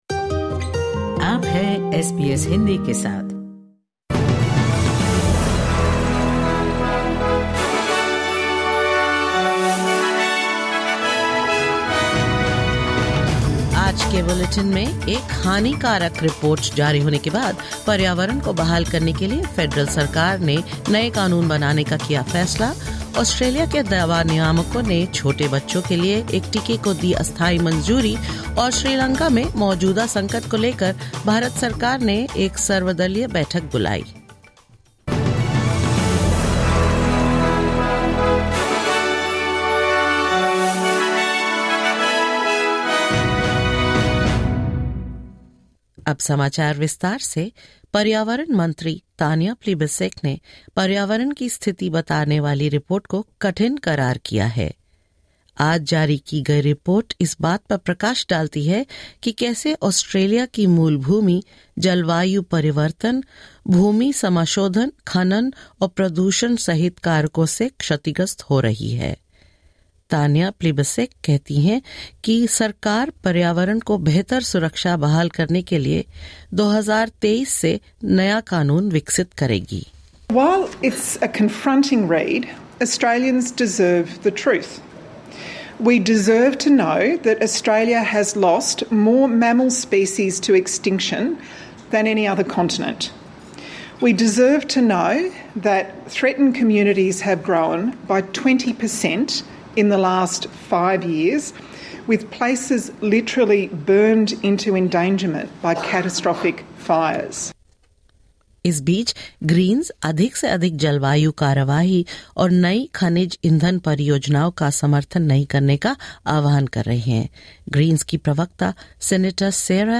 In this latest SBS Hindi bulletin: Tanya Plibersek says the government will develop new legislation for 2023 to restore the environment; Australia’s medicine regulators give tentative approval to Moderna's COVID-19 vaccine for young children; Indian government holds an all-party meet on Sri Lanka crisis and more